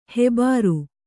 ♪ hebāru